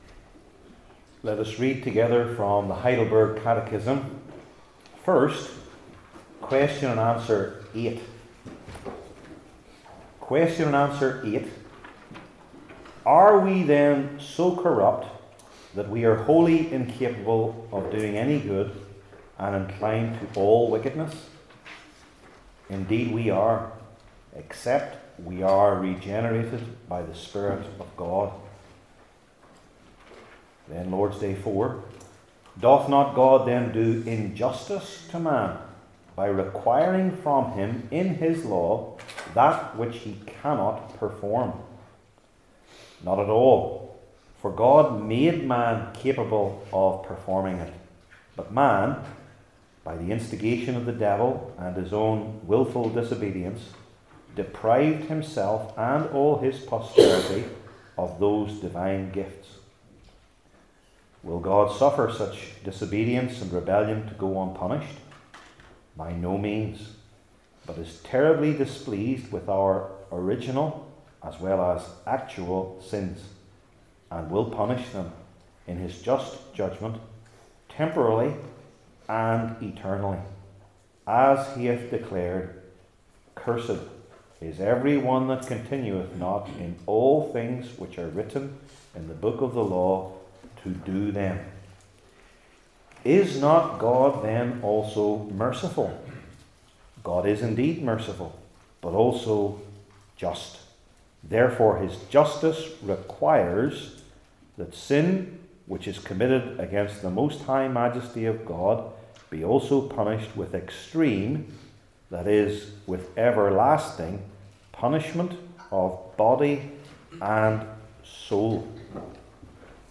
Heidelberg Catechism Sermons I. What They Are II.